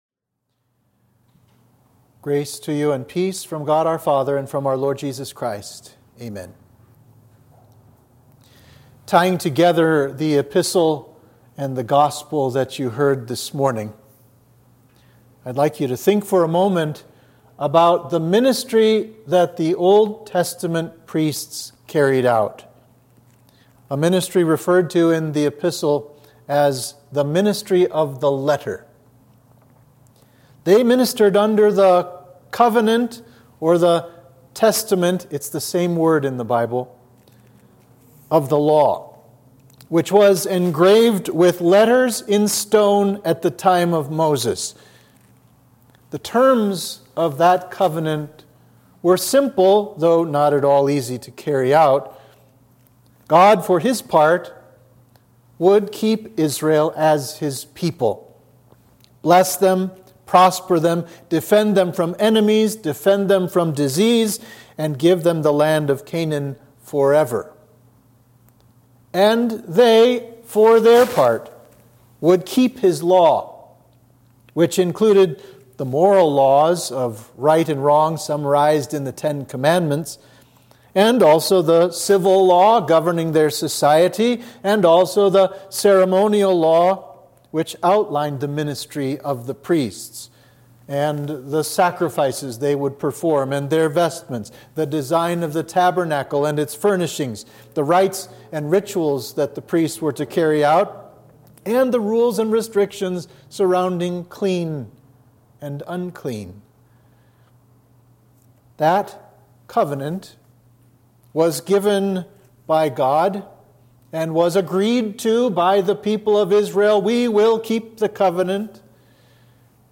Sermon for Trinity 12